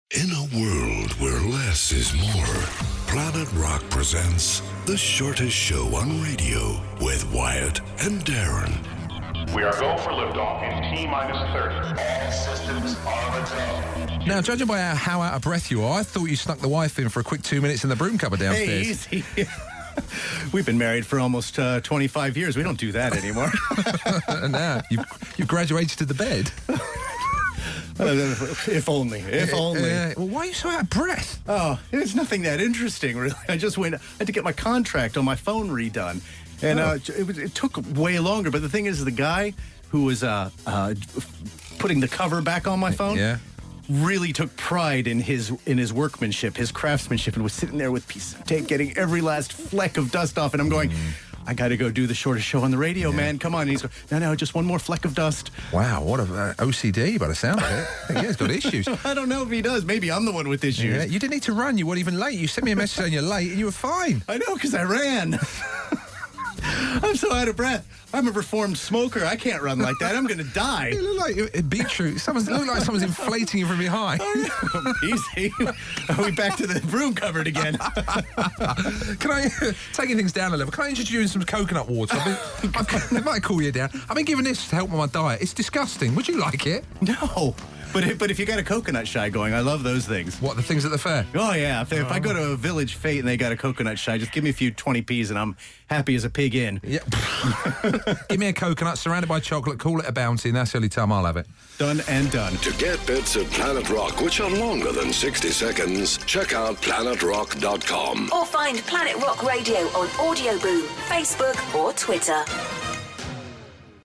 Out of breath.